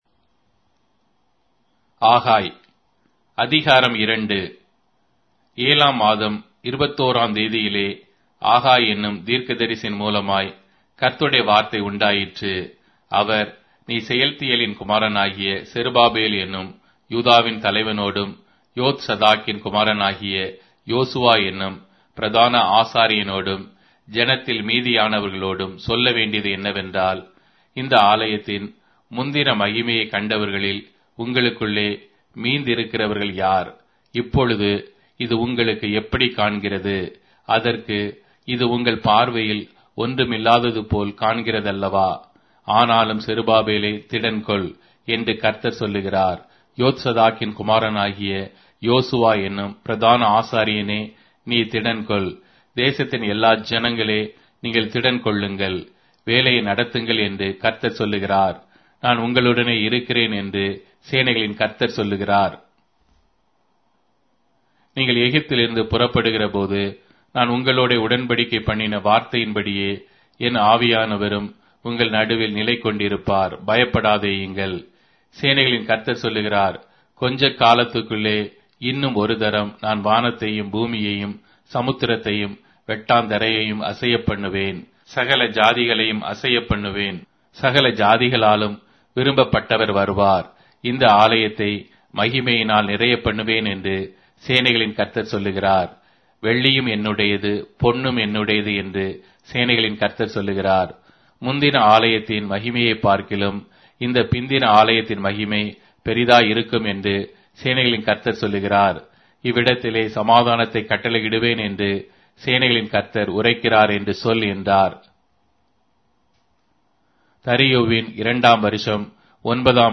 Tamil Audio Bible - Haggai 1 in Ervmr bible version